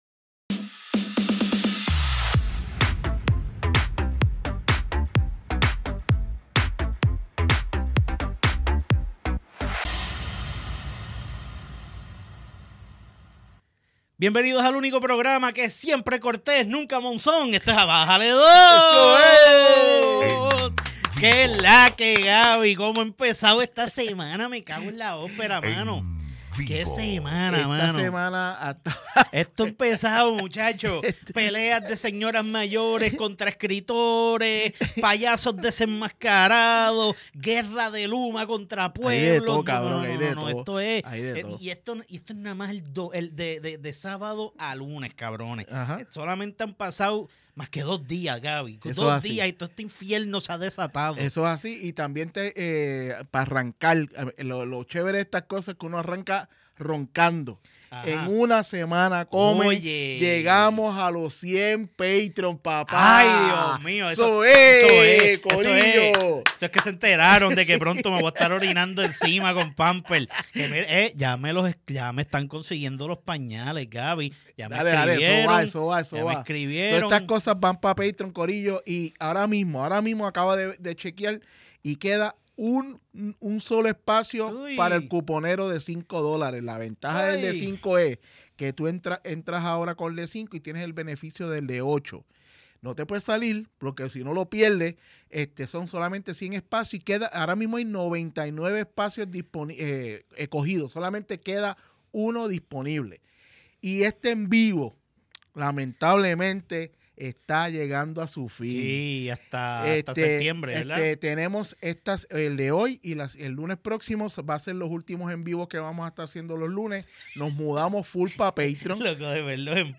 ¡Hoy es lunes y nos fuimos en vivo!